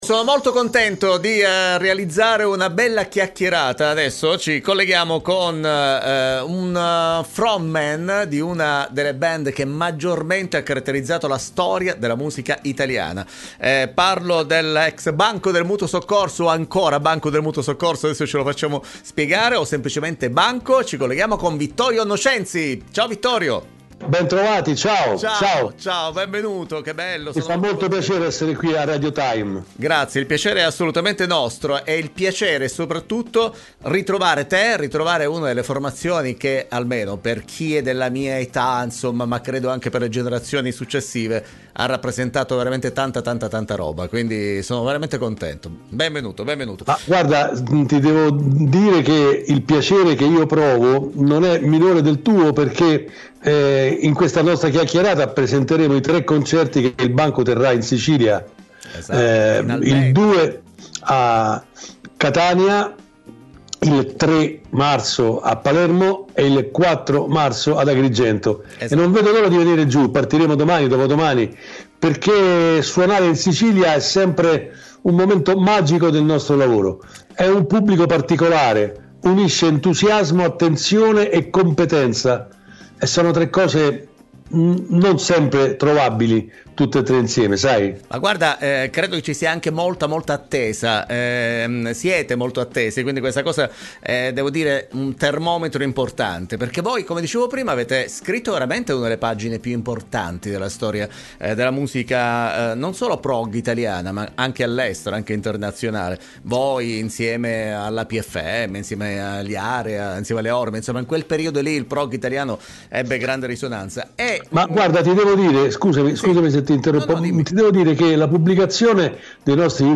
Doc Time intervista Vittorio Nocenzi 2-3-2023